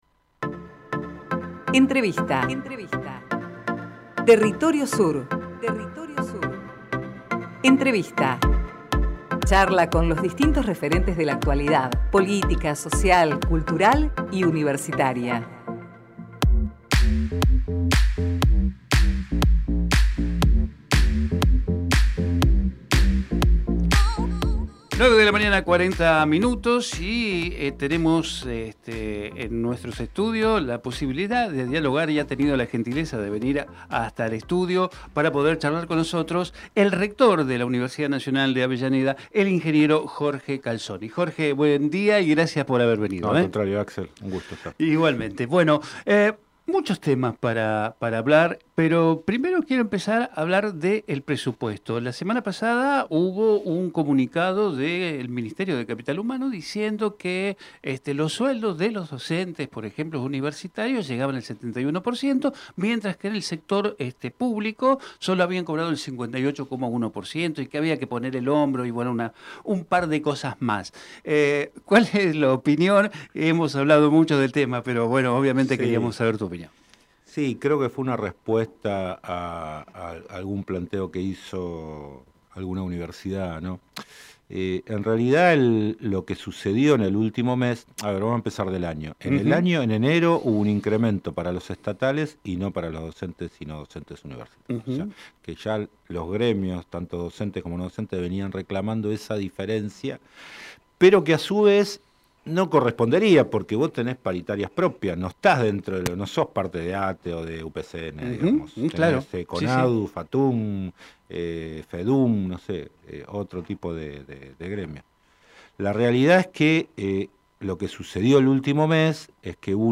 TERRITORIO SUR - JORGE CALZONI Texto de la nota: Compartimos con ustedes la entrevista realizada en Territorio Sur al Ing. Jorge Calzoni, Rector de la Universidad Nacional de Avellaneda.